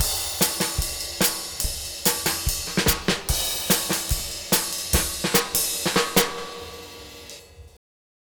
Twisting 2Nite 1 Drumz.wav